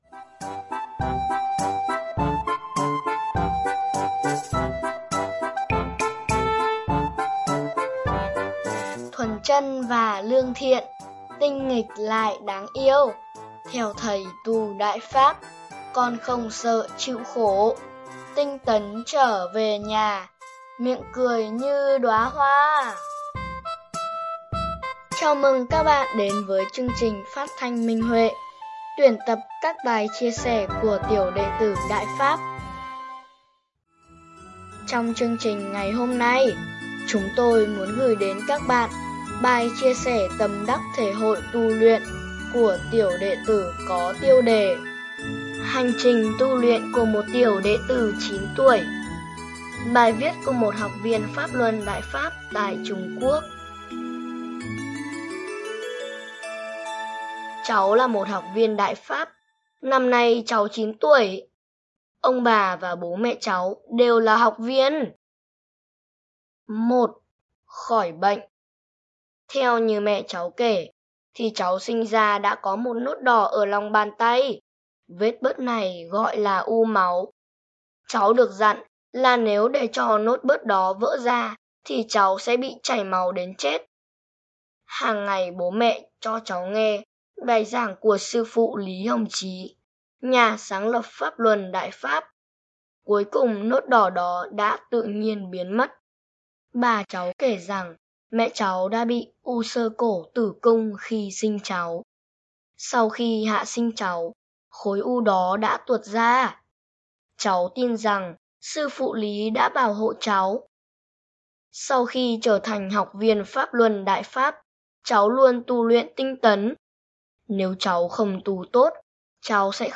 Chào mừng các bạn đến với chương trình phát thanh Minh Huệ, chuyên mục tuyển tập các bài chia sẻ của tiểu đệ tử Đại Pháp.